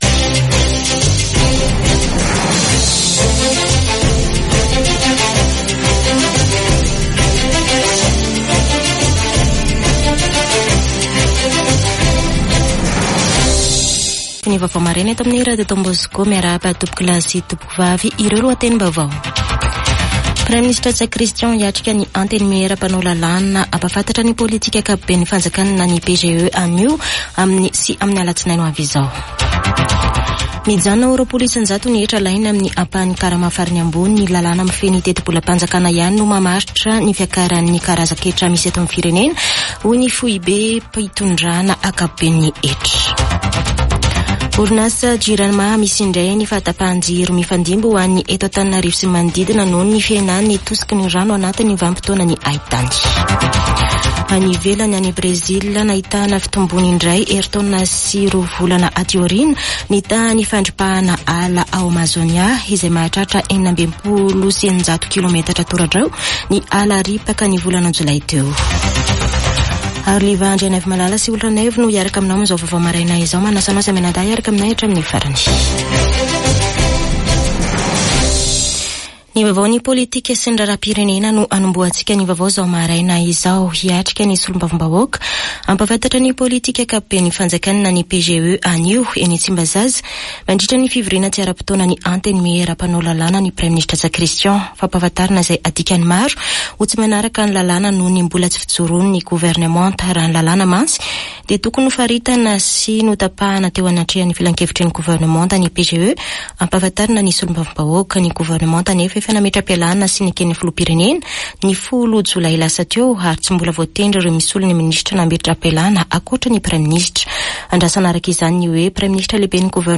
[Vaovao maraina] Zoma 9 aogositra 2024